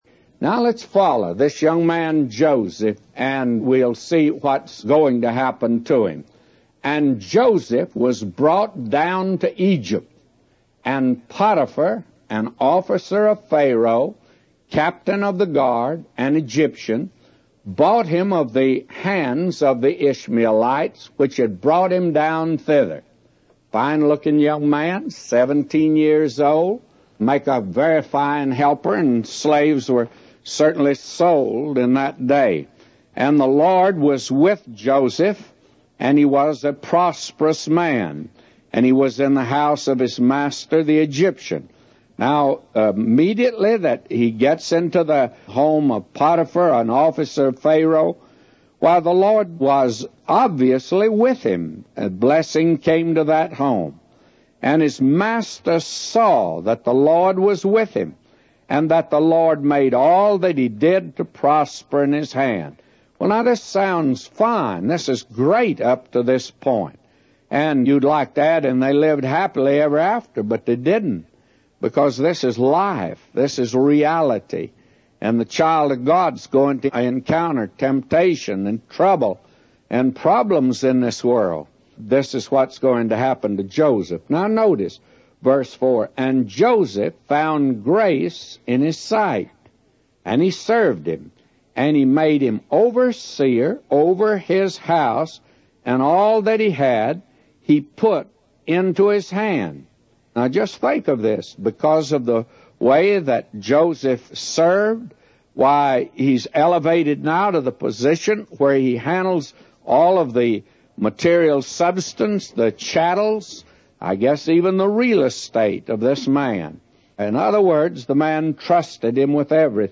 A Commentary By J Vernon MCgee For Genesis 39:1-999